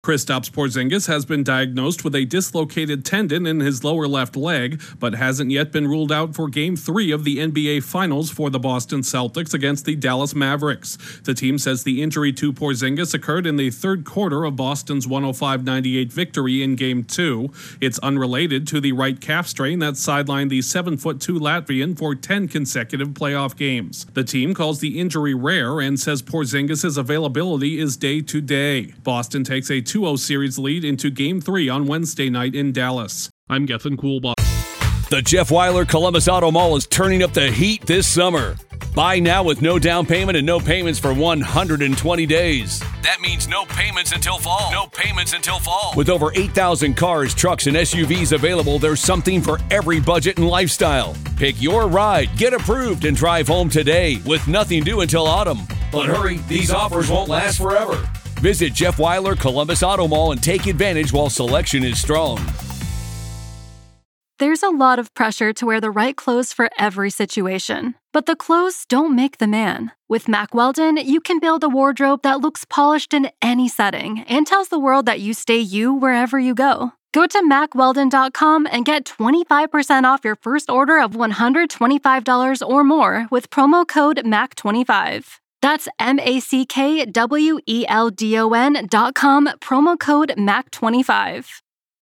The availability of one Boston Celtics star is in jeopardy for the remainder of the NBA Finals. Correspondent